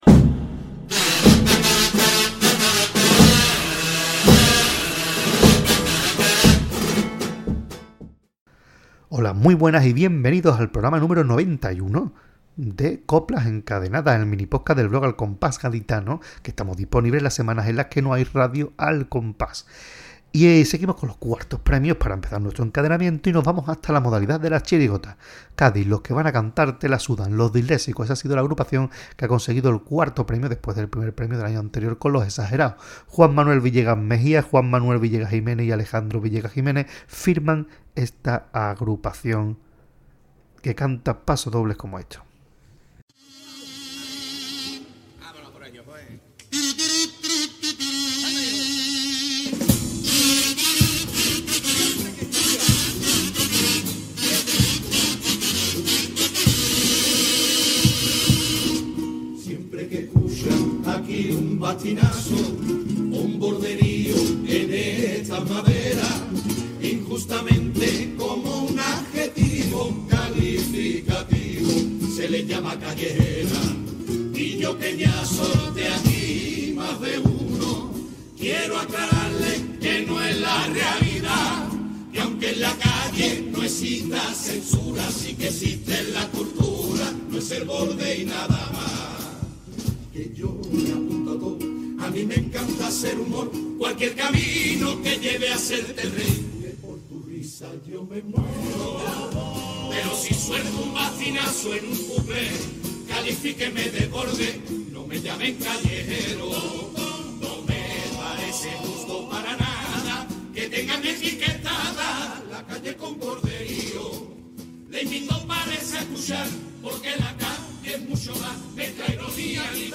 modalidad de chirigota
Pasodoble
Tango